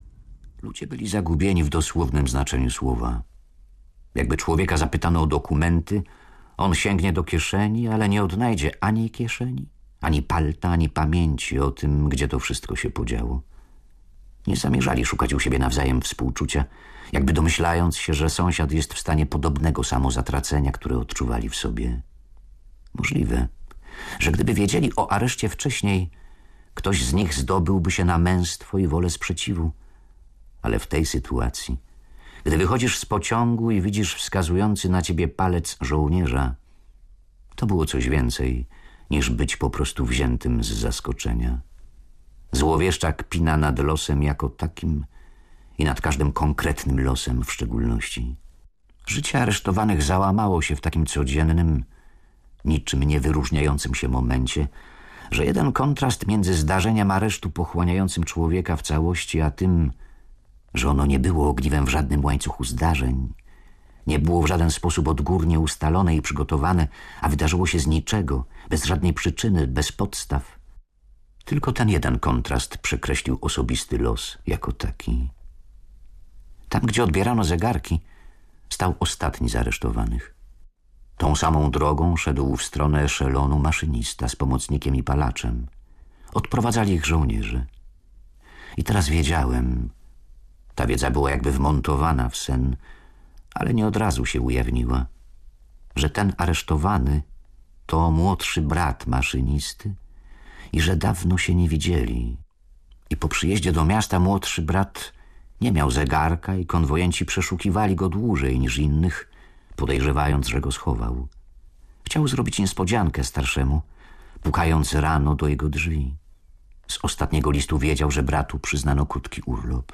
Granica zapomnienia w Jedynce Polskiego Radia